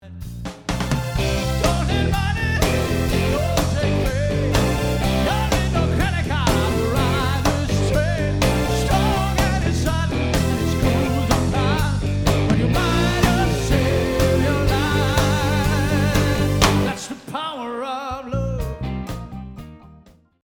partyband, coverband, dansemusik
det levende og professionelle 5 mandsorkester
• Allround Partyband